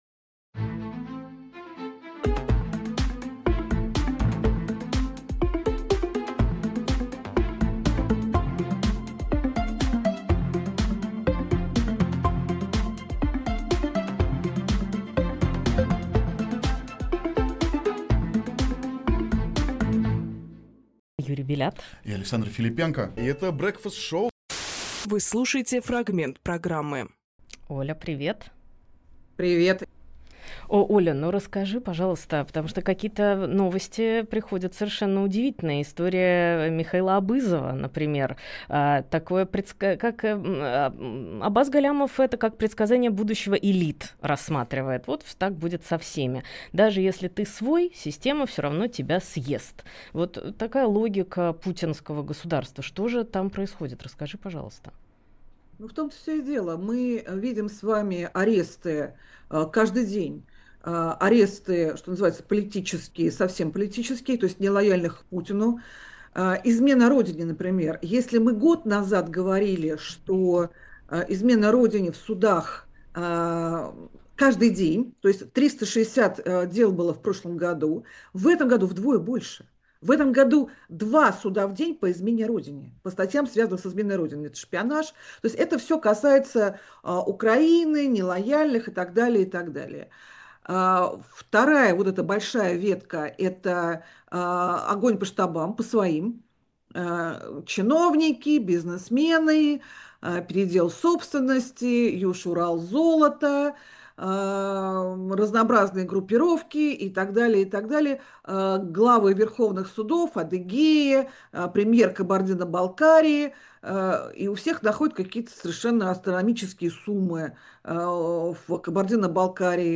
Фрагмент эфира от 05.10.25